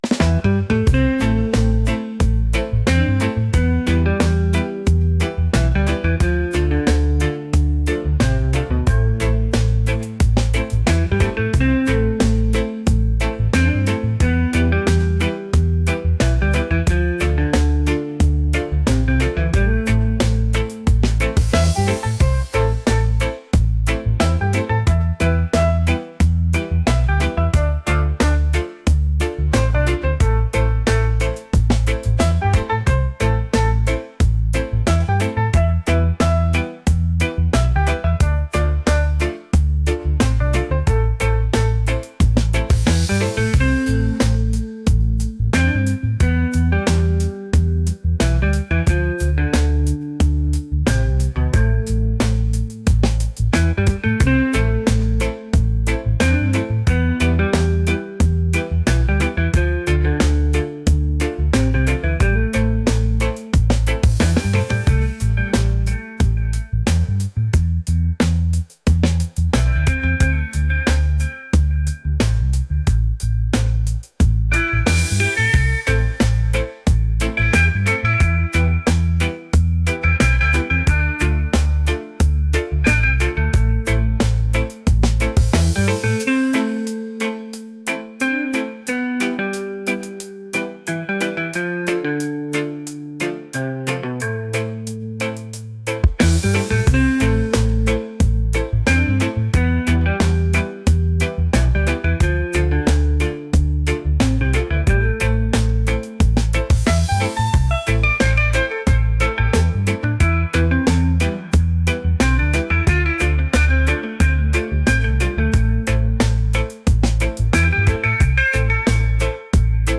reggae | groovy | relaxed